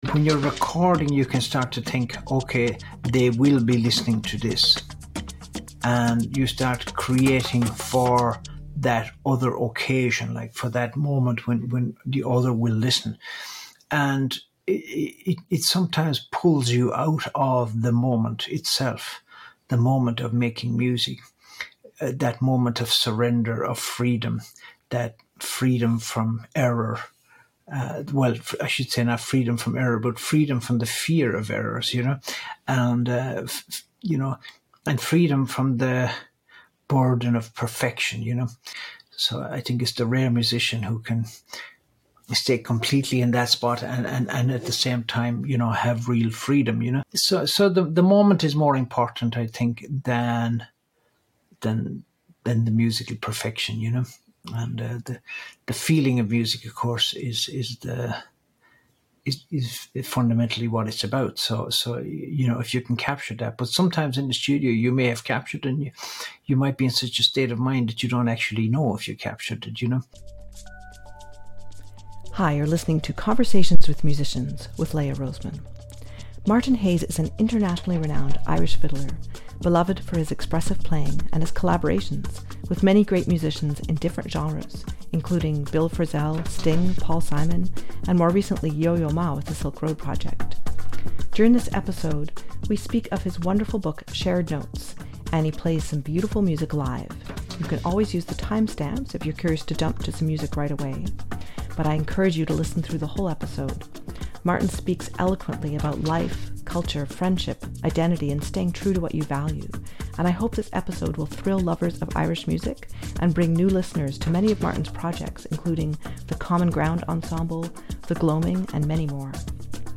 During this episode we spoke of his wonderful book Shared Notes, and he plays some beautiful music live (you can always use the timestamps if you’re curious to jump to some music right away), but I encourage you to listen through the whole episode. Martin speaks eloquently about life, culture, friendship, identity, and staying true to what you value, and I hope this episode will thrill lovers of Irish music and bring new listeners to many of Martin’s projects including The Common Ground Ensemble, The Gloaming, and many more. We talked about the loss and legacy of Dennis Cahill, how he met Thomas Bartlett, the rich depths of traditional music that Martin learned from the older generation, and the rhythm of life growing up on the farm in County Clare.